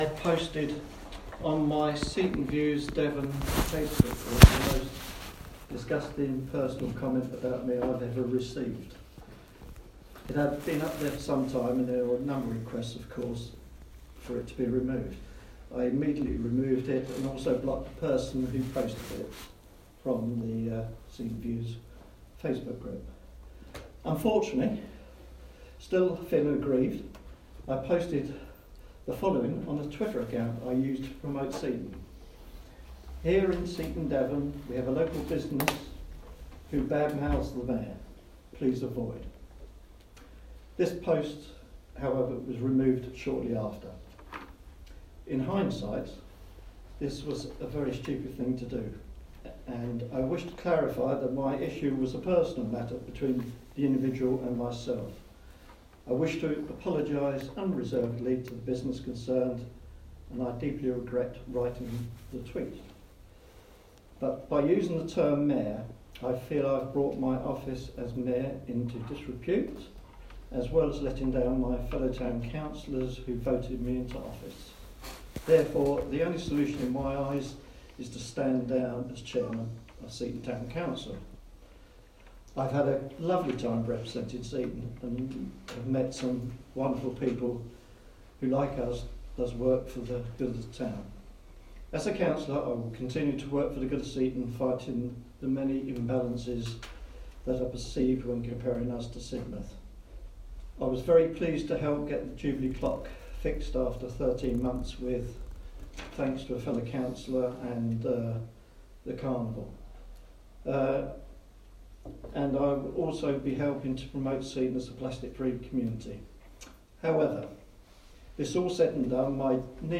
The audio file below is taken from tonight’s Seaton Town Council meeting where Mayor Peter Burrows resigns after saying he brought the office into disrepute with an “offensive” remark (since deleted) where he criticised a local (un-named) business using his official title.